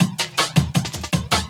12 LOOP13 -L.wav